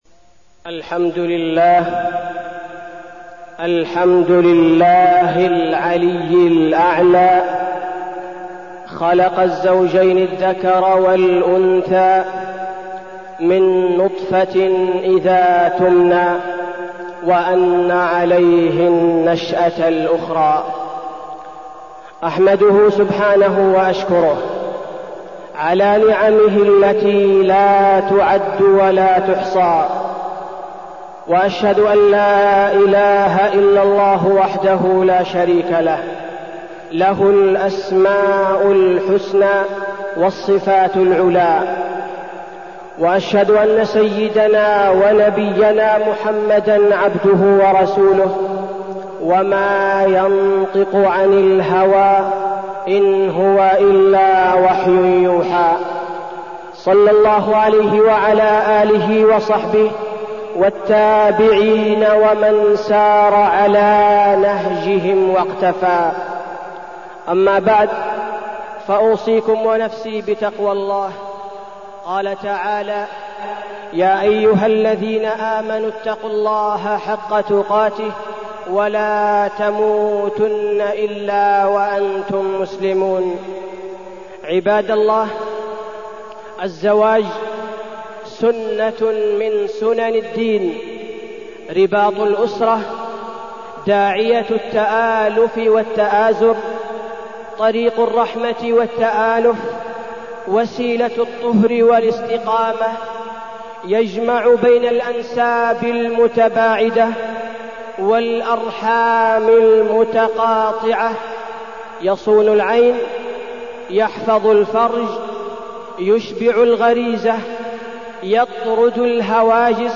تاريخ النشر ١ ربيع الثاني ١٤١٩ هـ المكان: المسجد النبوي الشيخ: فضيلة الشيخ عبدالباري الثبيتي فضيلة الشيخ عبدالباري الثبيتي الزواج